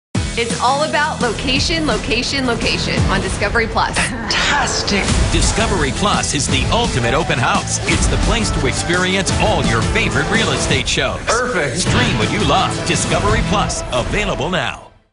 Joe CiprianoReal Estate Shows on Discovery+Promos Download This Spot